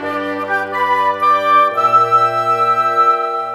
Rock-Pop 01 Winds 05.wav